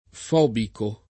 fobico [ f 0 biko ]